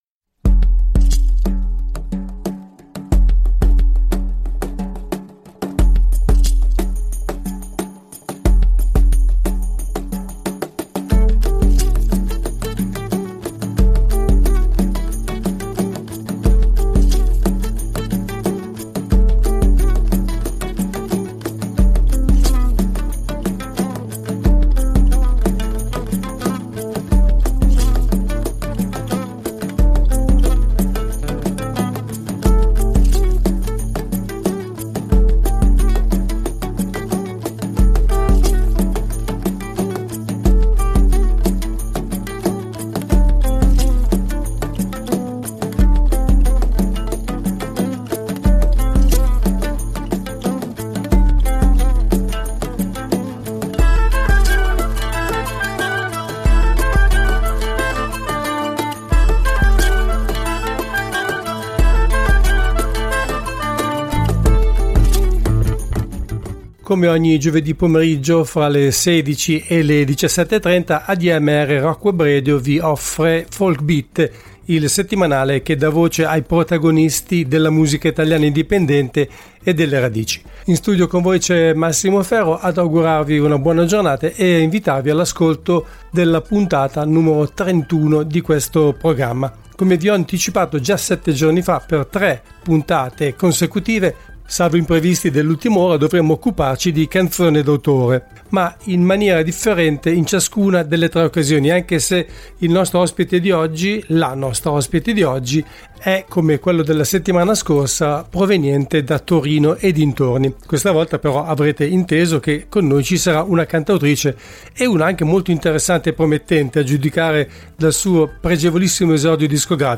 Ospite del programma al telefono